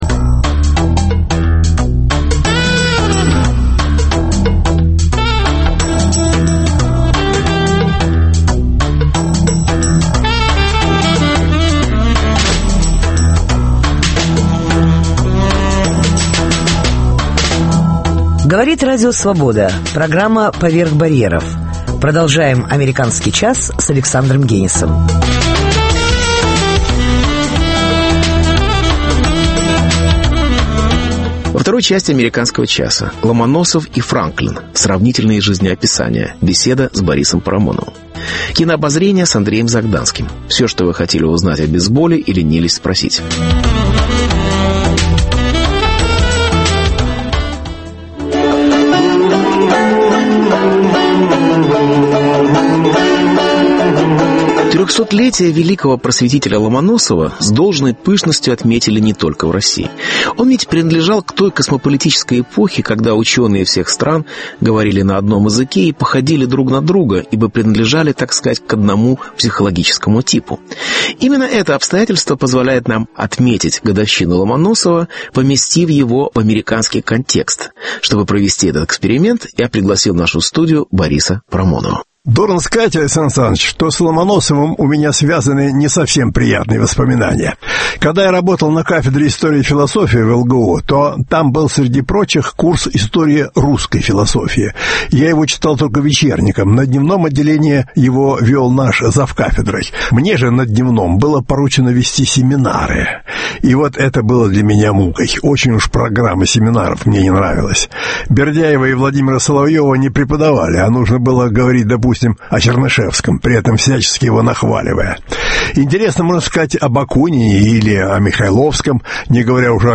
Ломоносов и Франклин. (Беседа с Борисом Парамоновым)